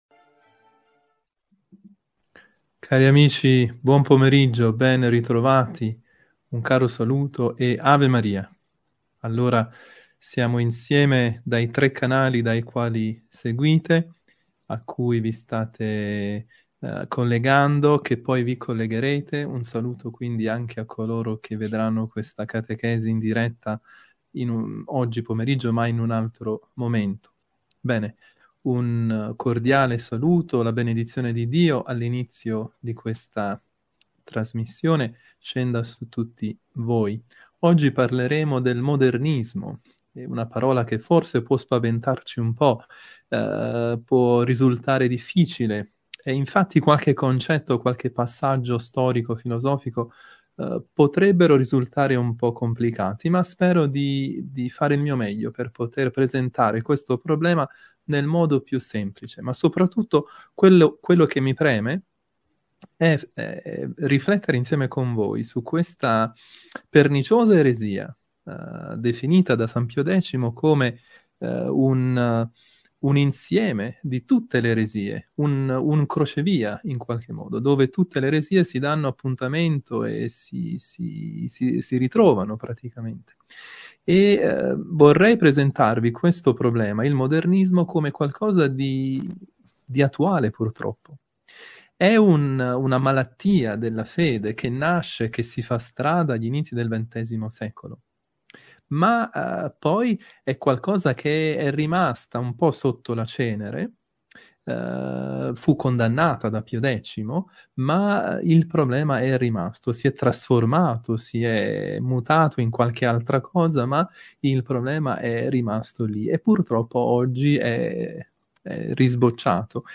Catechesi del lunedì